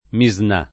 Misnà [ mi @ n #+ ] n. pr. f.